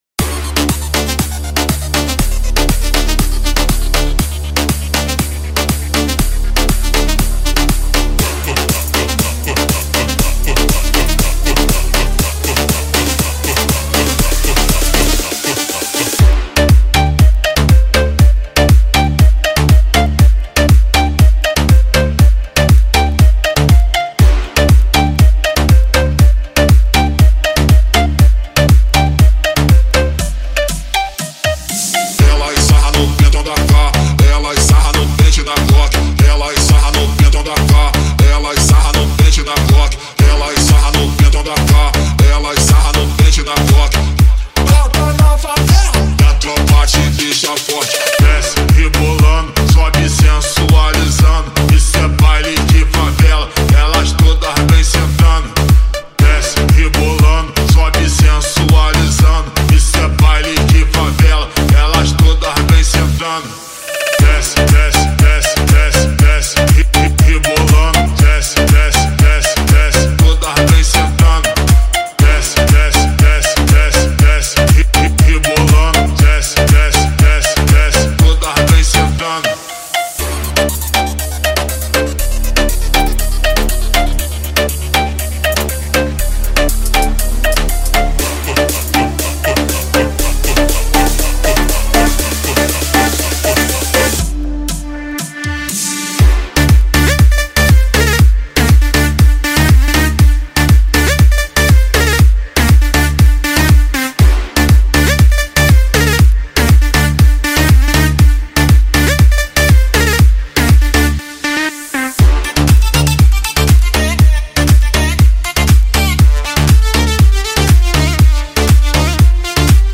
Party Vibes & Bass Boosted Remix